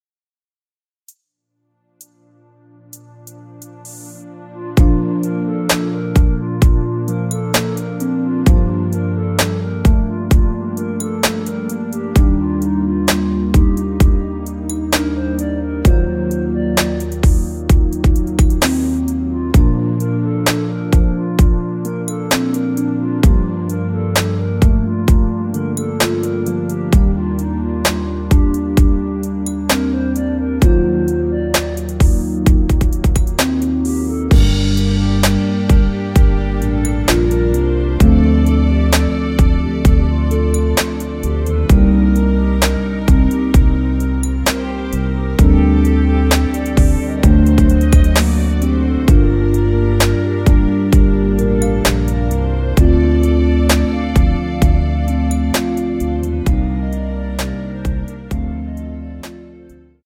원키에서(-1)내린 멜로디 포함된 MR 입니다.
Bb
앞부분30초, 뒷부분30초씩 편집해서 올려 드리고 있습니다.